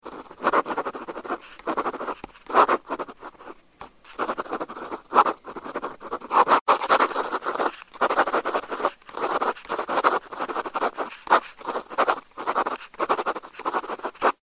На этой странице собраны звуки пишущей ручки: от легкого постукивания по бумаге до равномерного скольжения стержня.
Звук скольжения ручки по бумаге